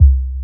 20BASS01  -R.wav